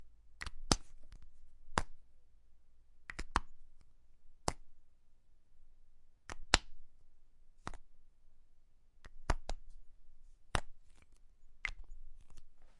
写作 " 马克笔帽的操作
描述：记录在带有SM81和便宜的akg SDC的SD 702上，不记得哪一个只是想要变化。不打算作为立体声录音只有2个麦克风选项。没有EQ不低端滚动所以它有一个丰富的低端，你可以驯服品尝。